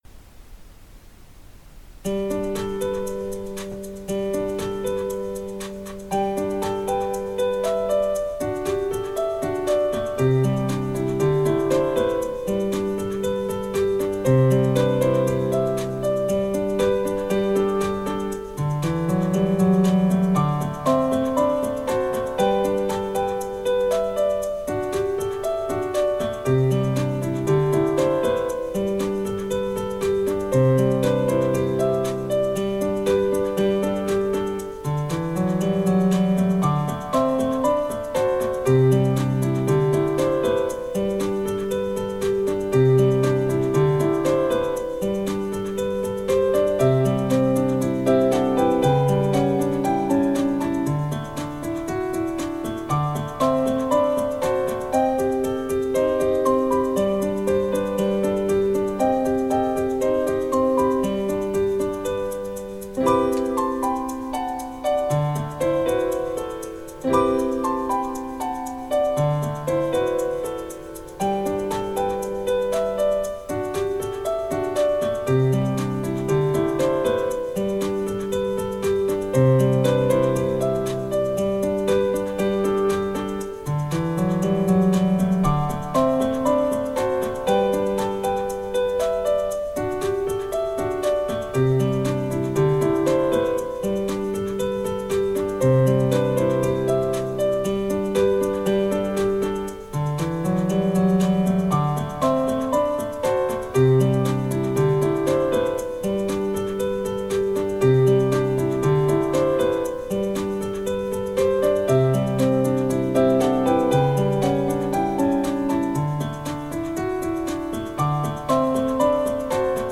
マイクロホンの位置は、フロントスピーカーから1.8ｍ離した高さ1mの場所。